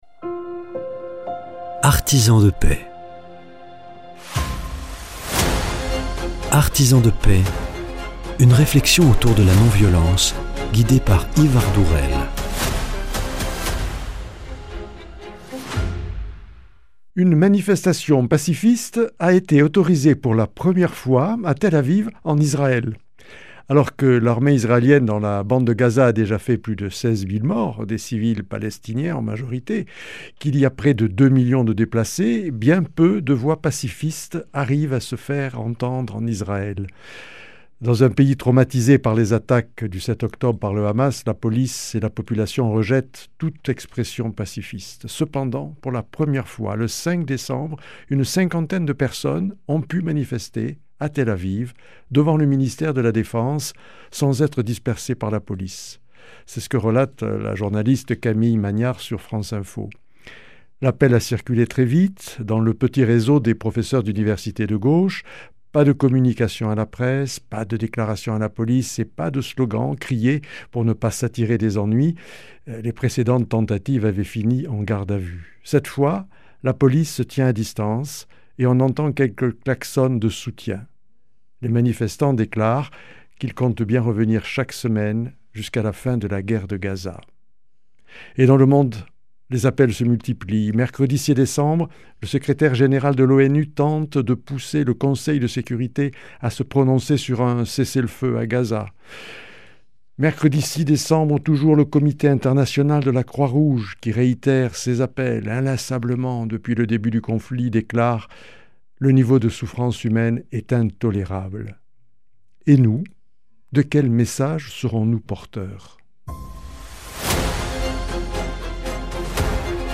La guerre à Gaza, bien peu de voix pacifistes arrivent à se faire entendre. Premier entretien